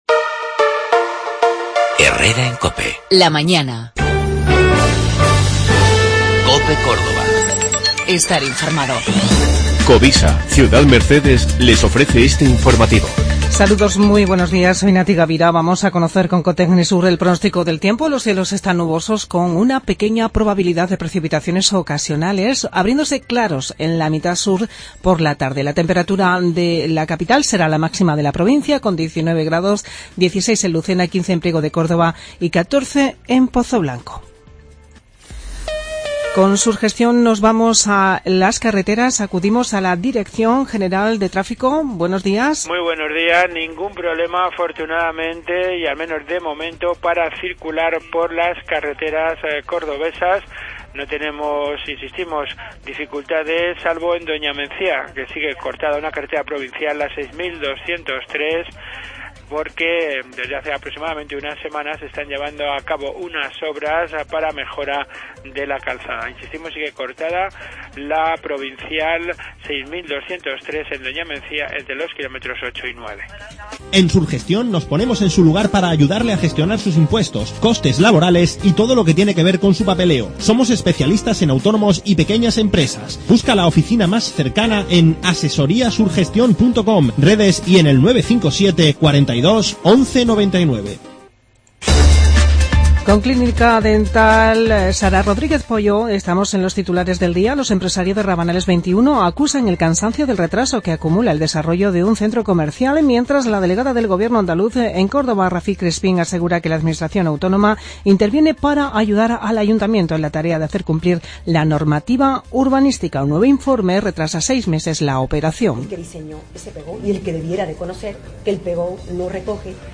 Informativo 4 de Febrero